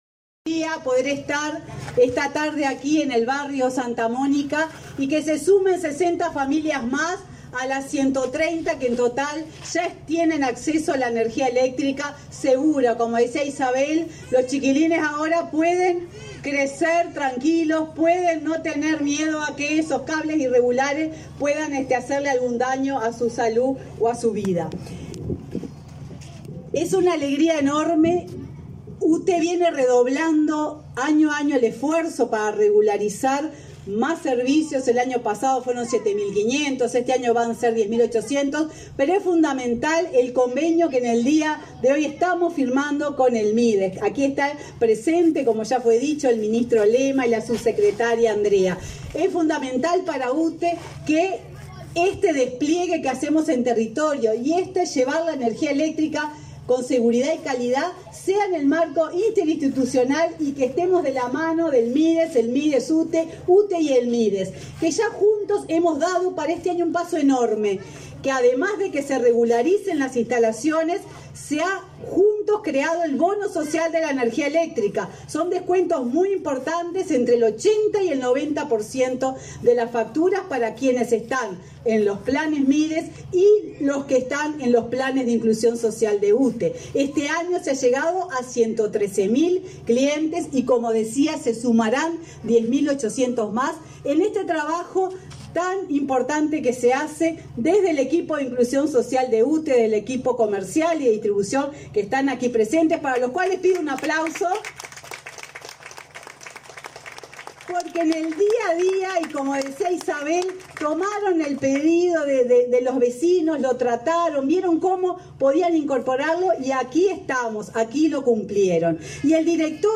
Conferencia de prensa por la firma de convenio entre Mides y UTE
Conferencia de prensa por la firma de convenio entre Mides y UTE 16/03/2022 Compartir Facebook X Copiar enlace WhatsApp LinkedIn El Ministerio de Desarrollo Social y UTE firmaron un convenio, este 16 de marzo, que establece acciones para el acceso de energía eléctrica en forma segura a los hogares en situación de vulnerabilidad social. Participaron el ministro Martín Lema y la presidenta de la empresa estatal, Silvia Emaldi.